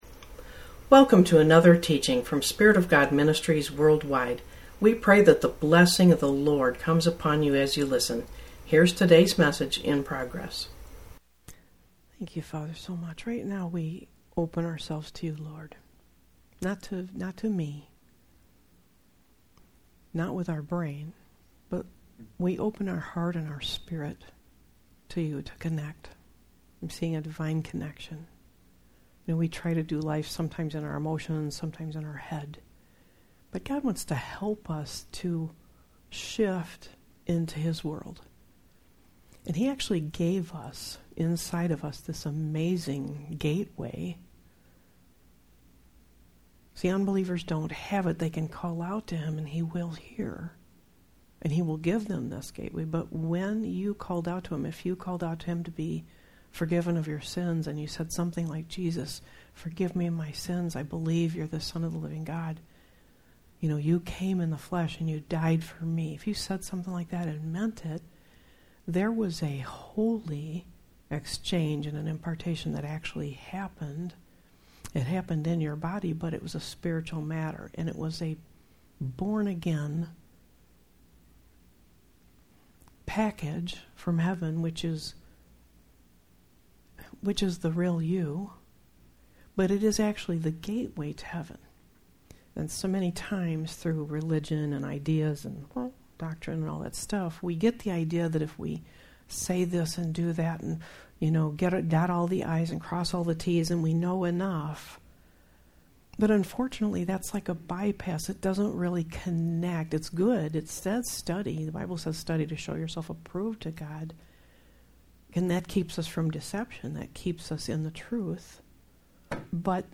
Sermons | Spirit Of God Ministries WorldWide